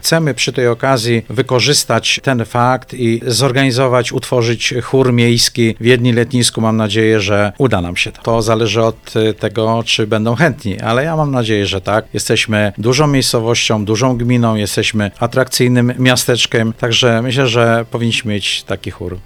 Informował na antenie Radia Radom burmistrz Piotr Leśnowolski.